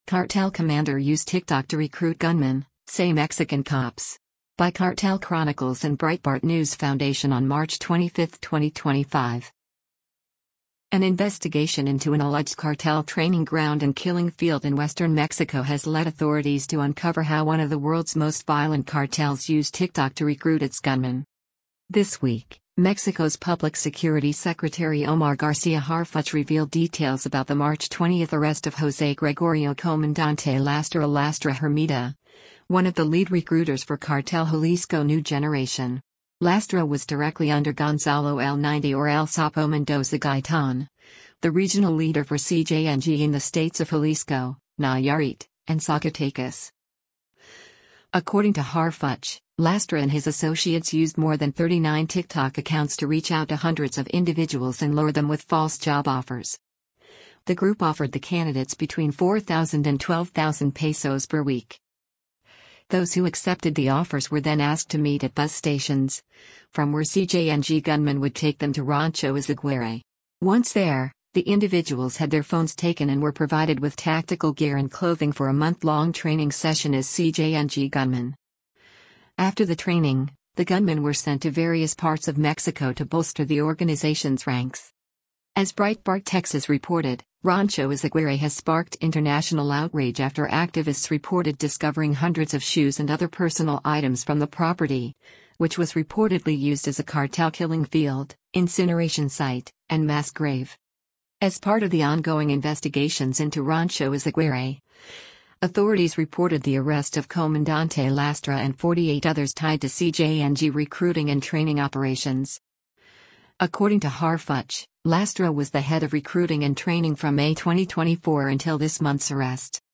Mexico's Public Security Secretary Omar Garcia Harfuch talks about the investigation into